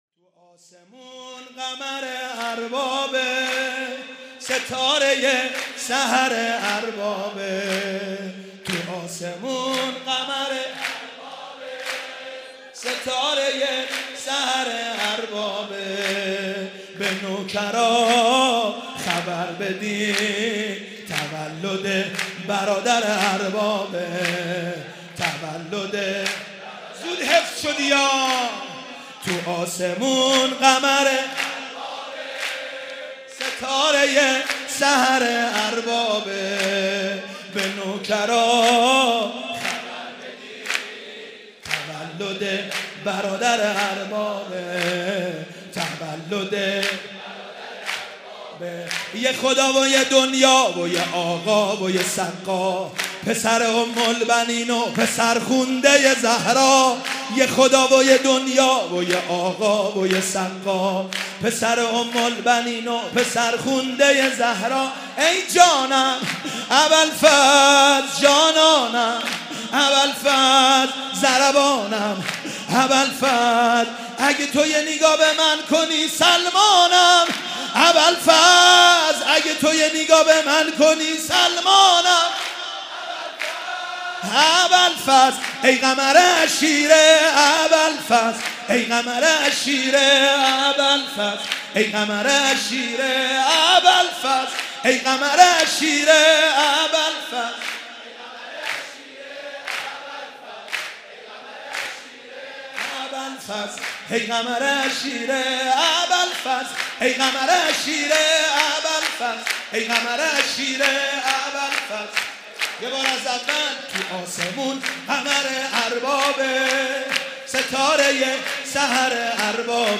شب میلاد امام حسین (ع)/هیأت آل یاسین قم
شب میلاد امام حسین (ع)در هیأت آل یاسین قم با مداحی
سرود